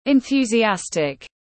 Enthusiastic /ɪnθju:zi’æstɪk/